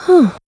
Ripine-Vox_Sigh.wav